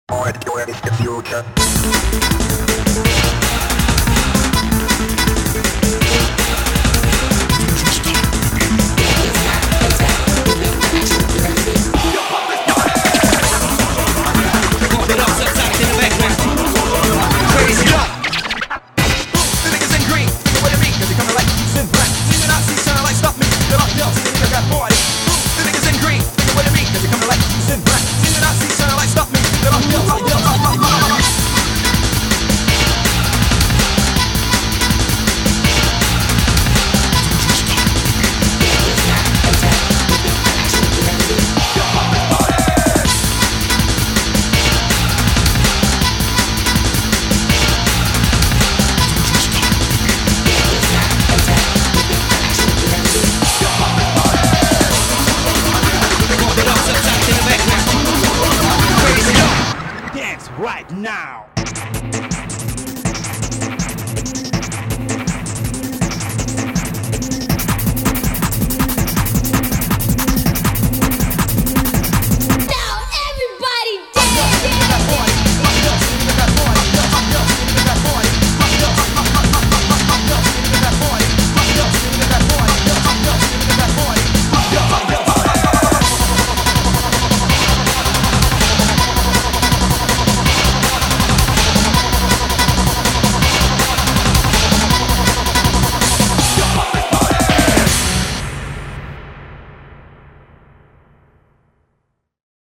BPM81-162